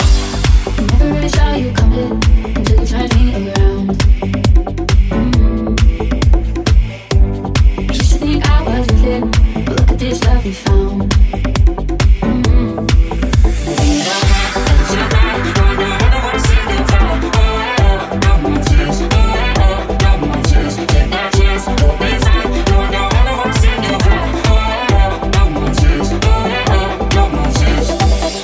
light deep house releases
Genere: deep, slap, tropical, ethno, remix